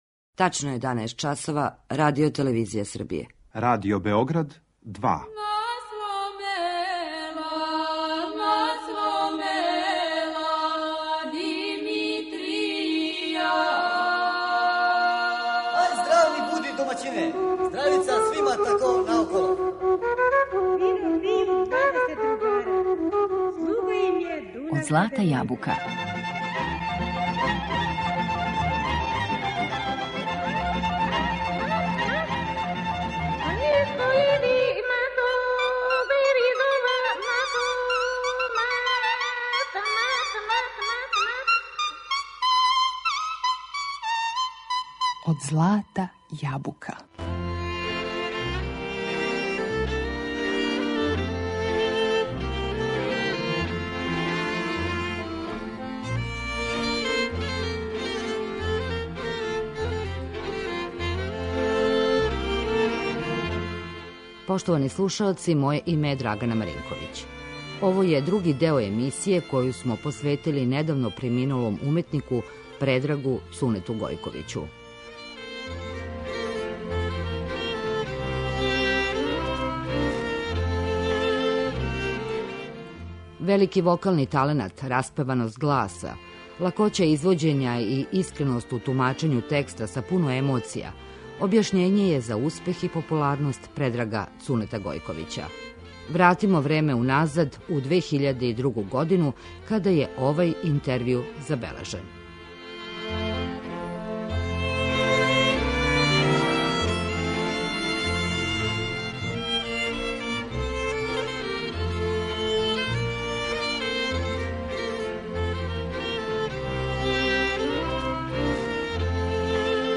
У знак сећања и дубоког поштовања, у две емисије слушаћете разговор који смо забележили са уметником Предрагом Цунетом Гојковићем 2002. године.